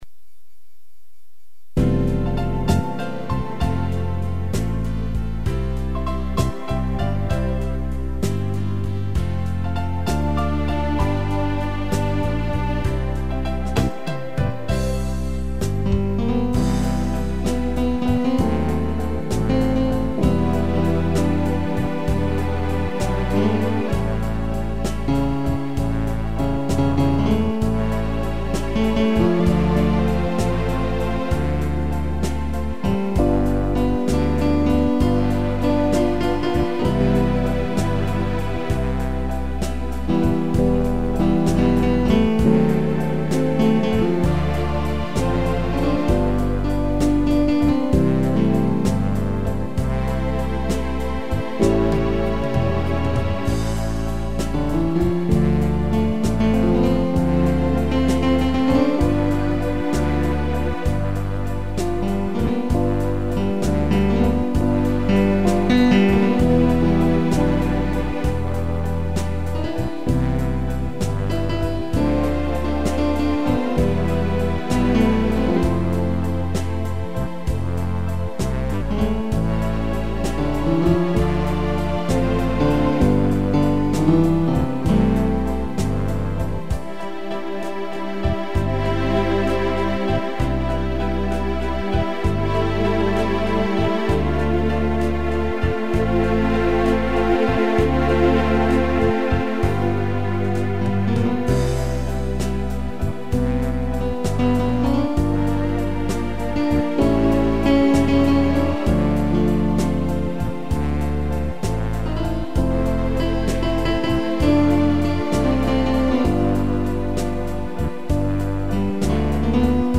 piano e tutti
instrumental